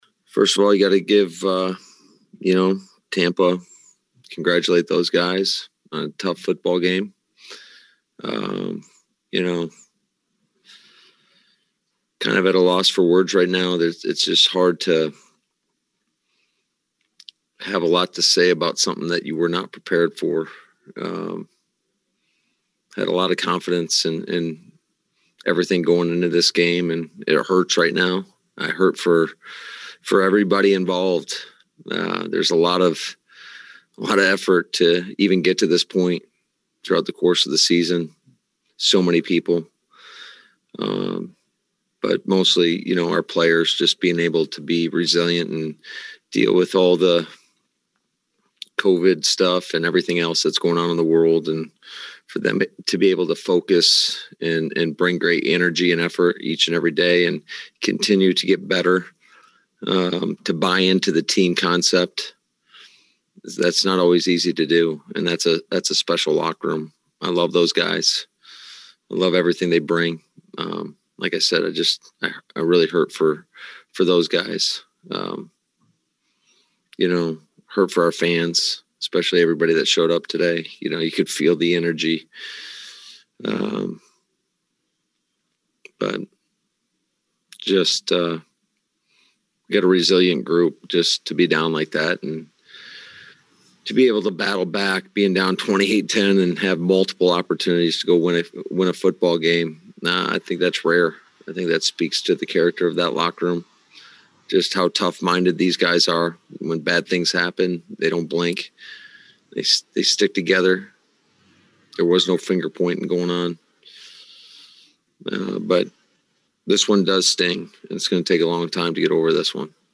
An ashen Matt LaFleur met the media: